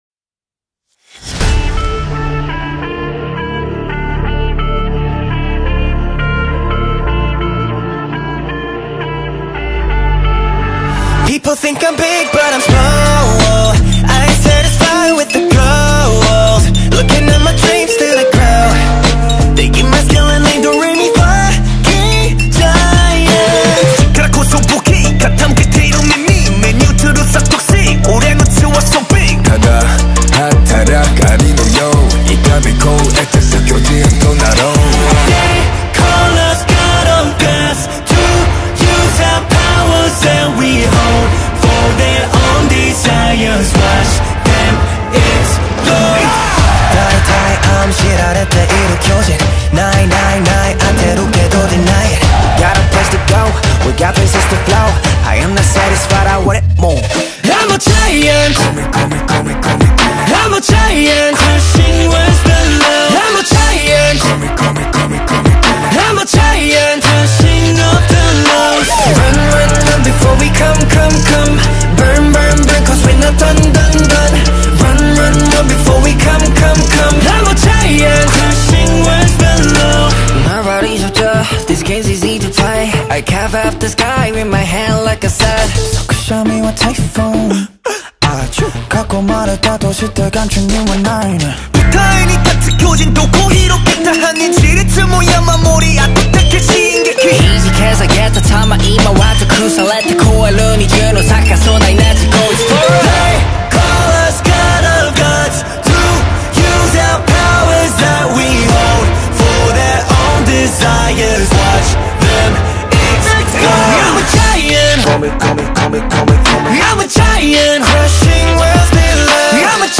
кейпоп